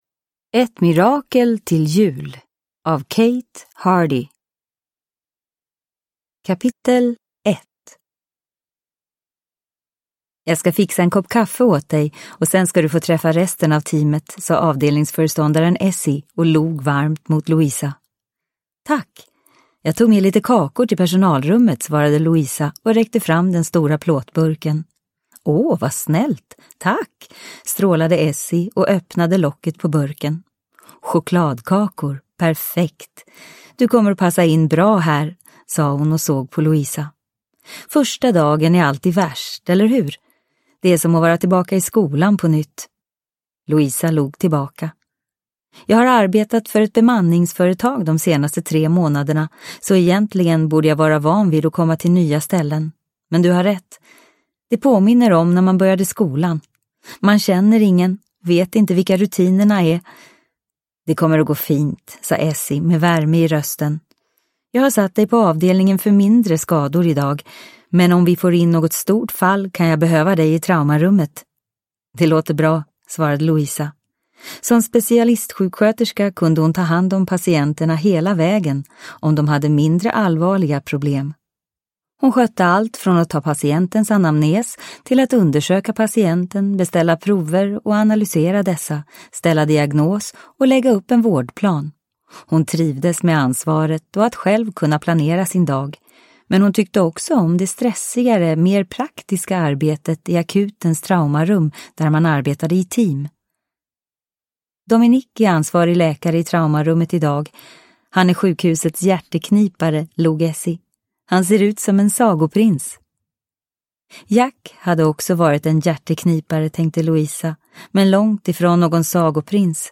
Ett mirakel till jul – Ljudbok – Laddas ner